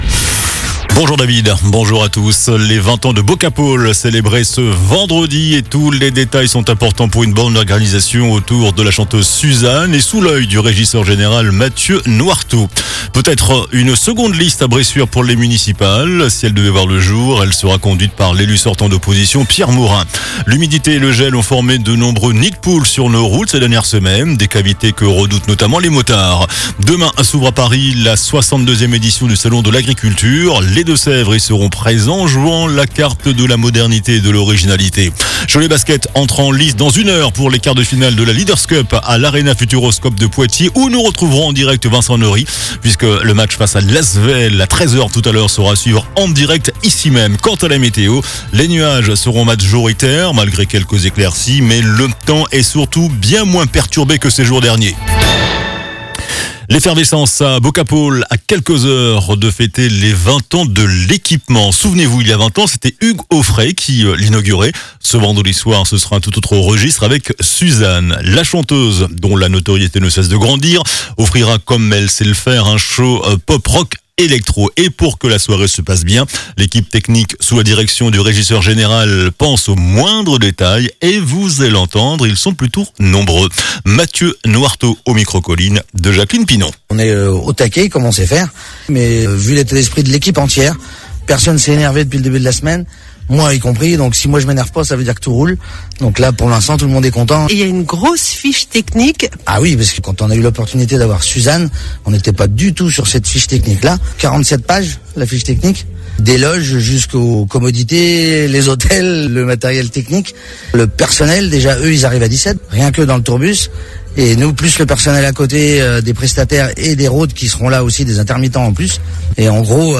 JOURNAL DU VENDREDI 20 FEVRIER ( MIDI )